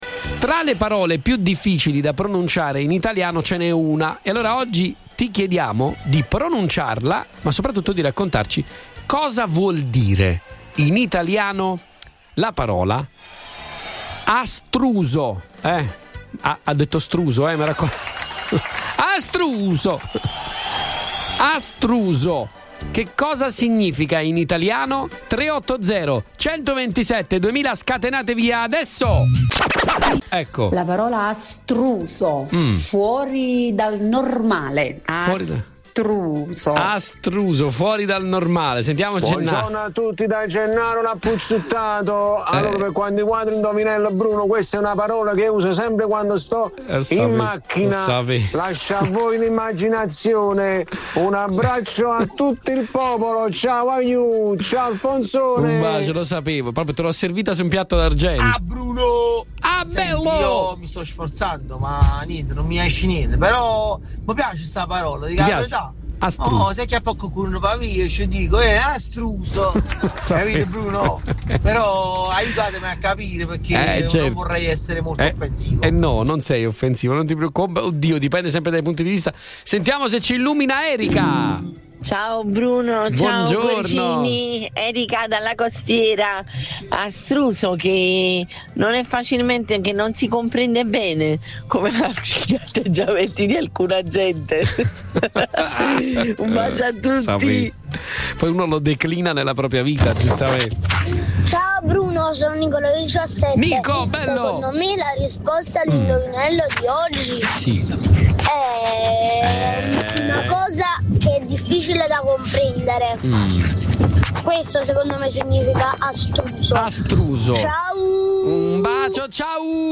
RIASCOLTA DA QUI I MESSAGGI VOCALI